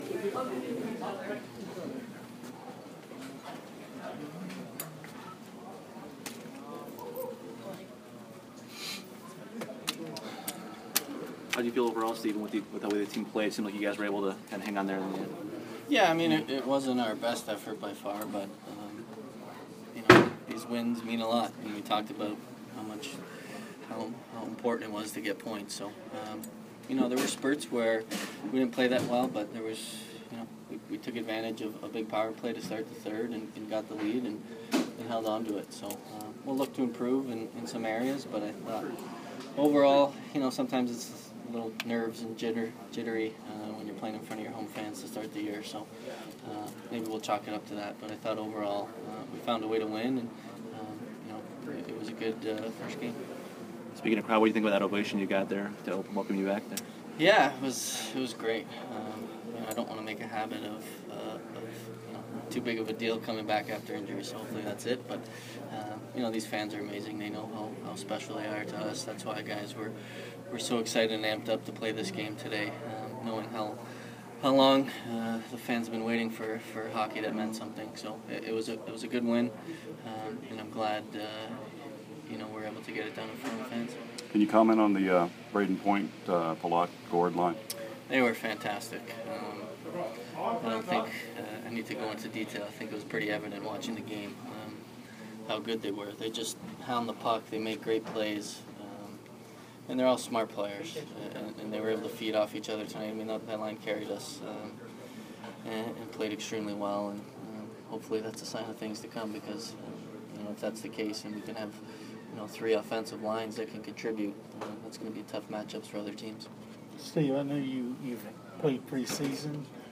Stamkos Post-Game Home Opener